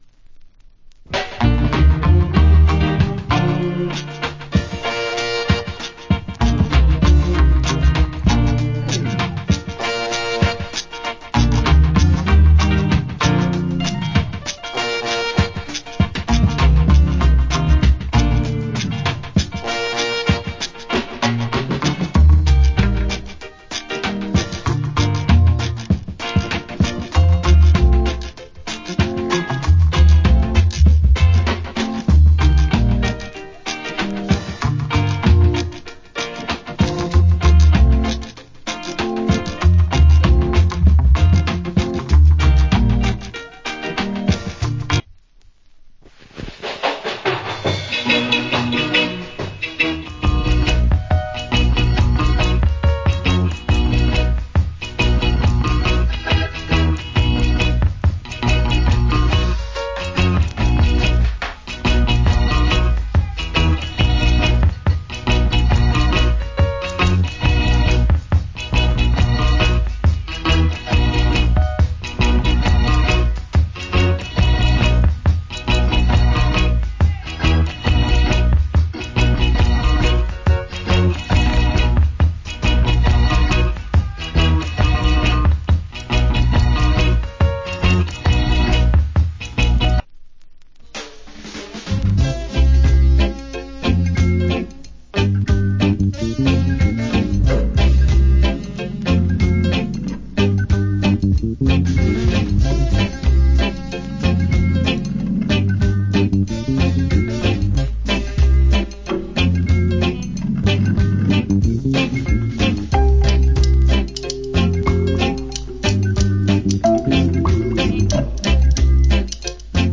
Nice Roots & Reggae